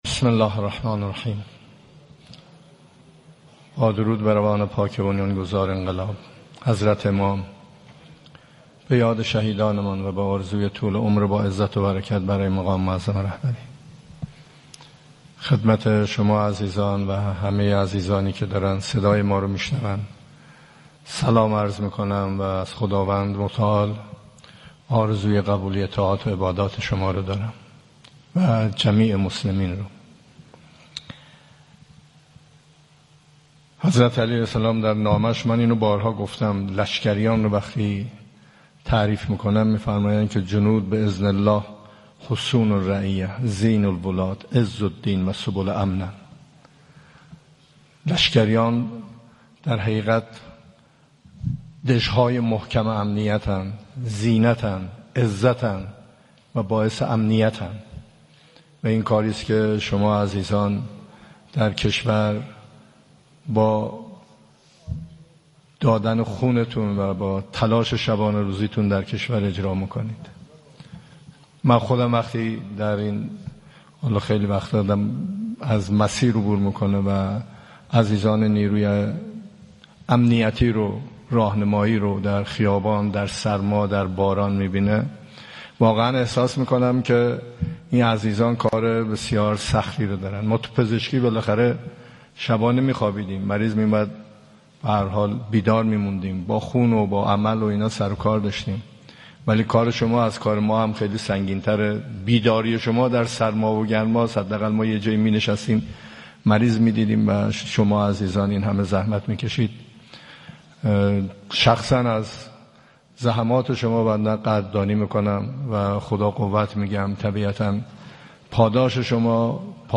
فیلم | سخنان پزشکیان در همایش سراسری روسا و معاونین پلیس راهور فراجا